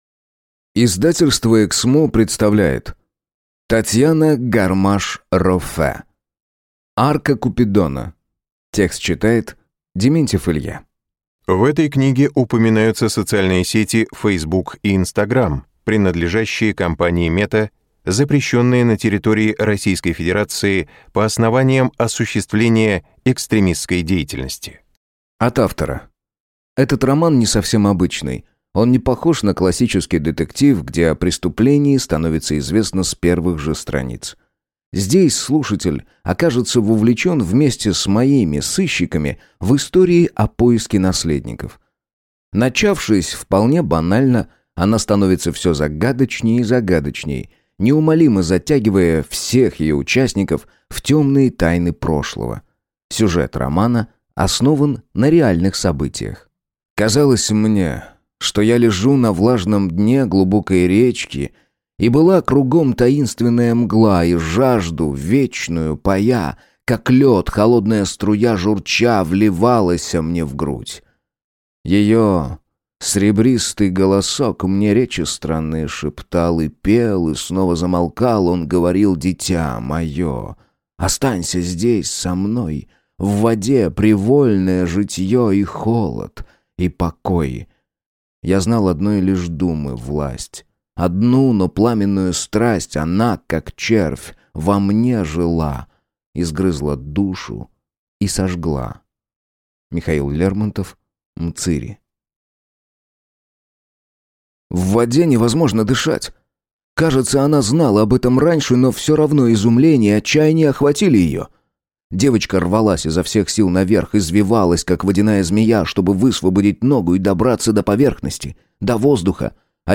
Аудиокнига Арка Купидона | Библиотека аудиокниг